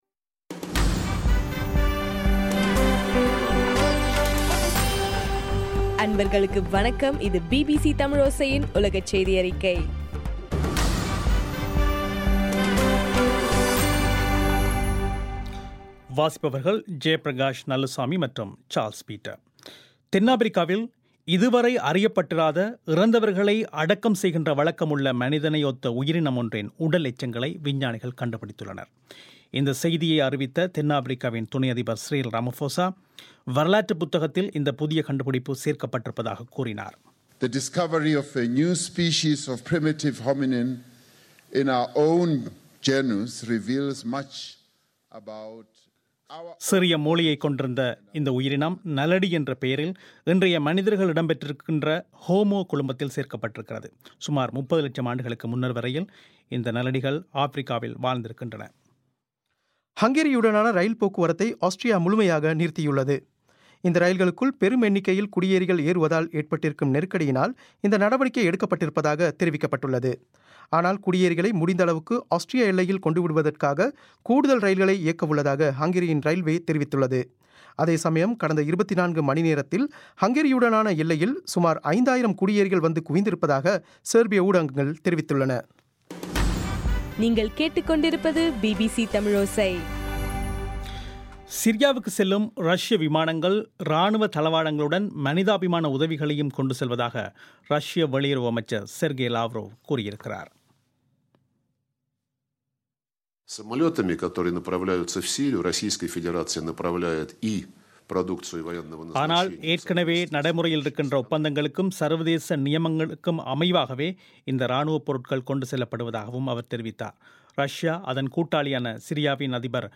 செப்டம்பர் 10 பிபிசியின் உலகச் செய்திகள்